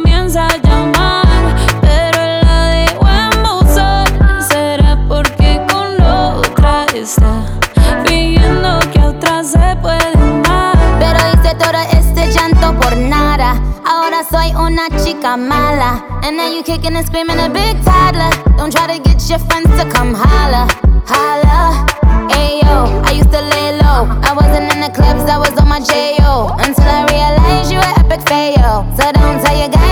Genre: Urbano latino